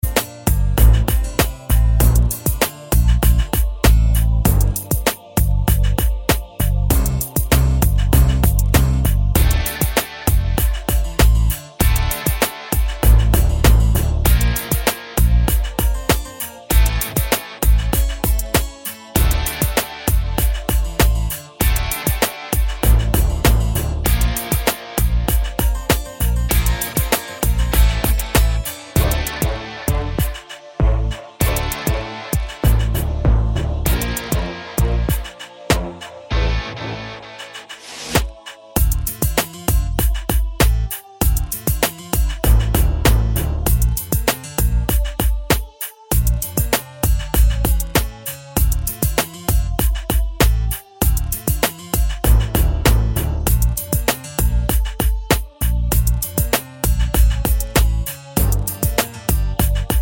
no Backing Vocals Rock 4:59 Buy £1.50